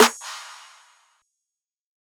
Metro Claps [Vibe].wav